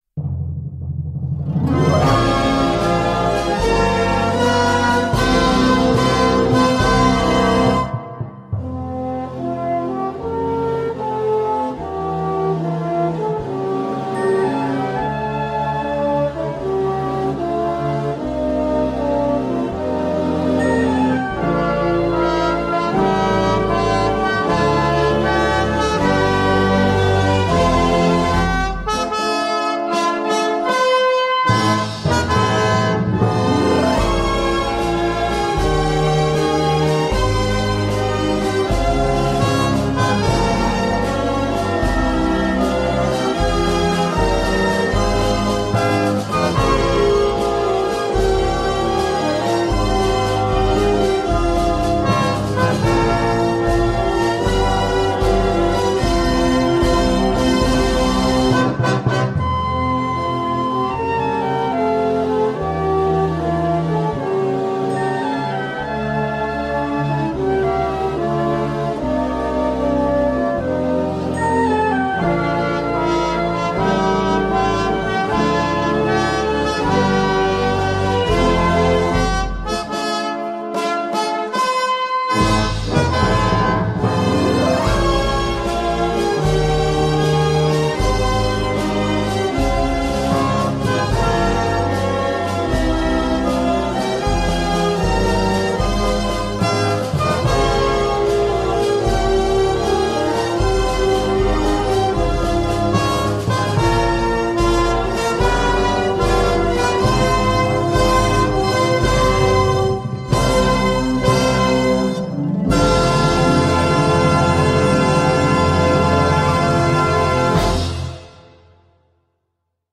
полная инструментальная версия